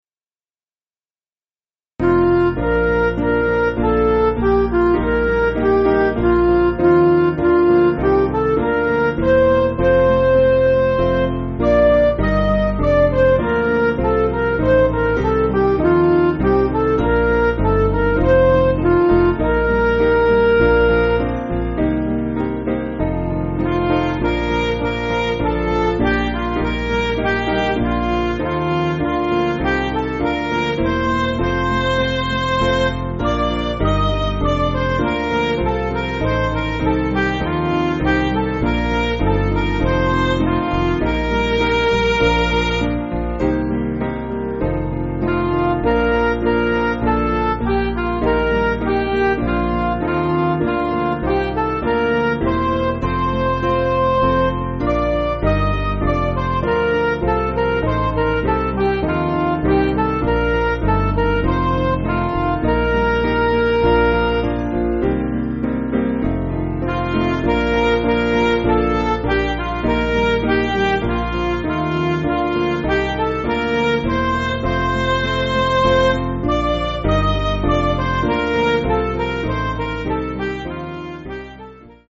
Piano & Instrumental
(CM)   6/Bb